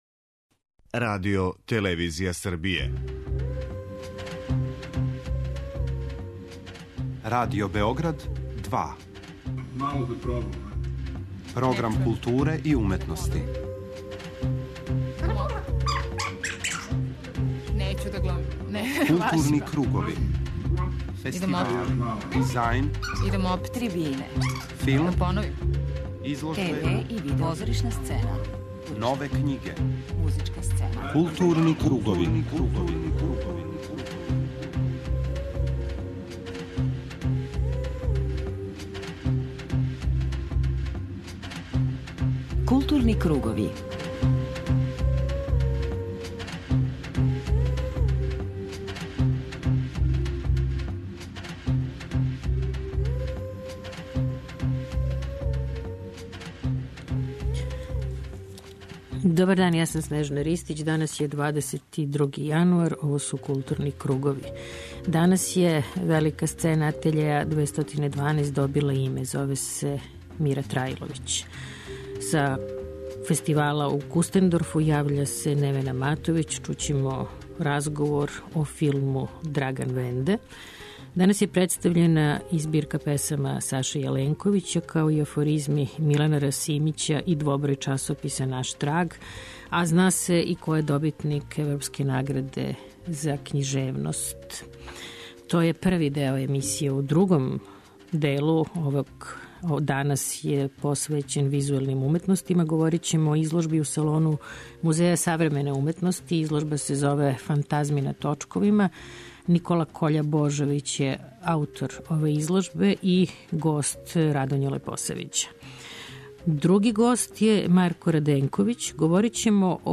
У првом делу емисије, између осталог, чућемо извештај са седмог Међународног филмског и музичког фестивала Кустендорф, који се одржава на Мокрој гори.